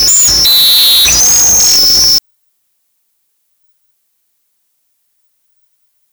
Ammodramus humeralis - Chingolo ceja amarilla
chingolocejaamarilla.wav